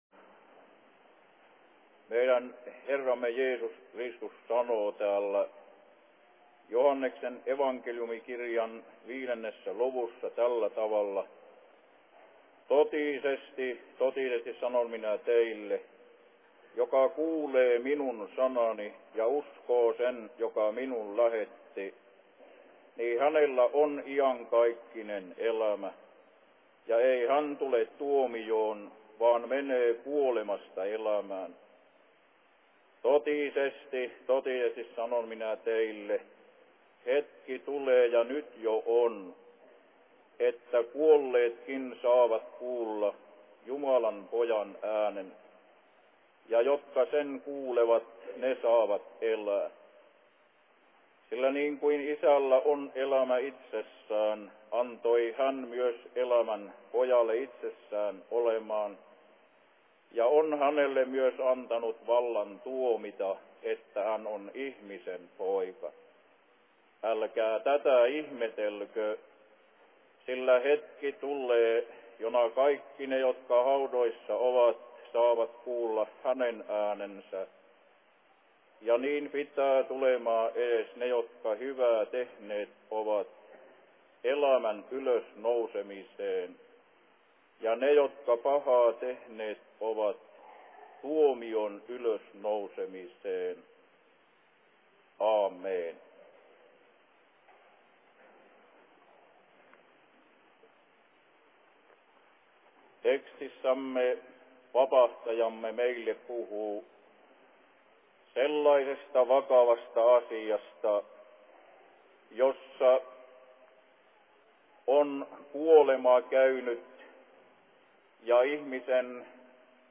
Aattoseurat Kallion kirkossa/Seurapuhe 1959
Paikka: 1959 Suviseurat Helsingin Messuhallissa